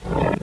wolt_run_grunt2.wav